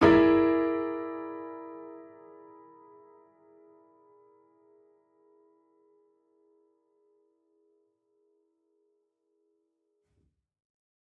Index of /musicradar/gangster-sting-samples/Chord Hits/Piano
GS_PiChrd-E7b5.wav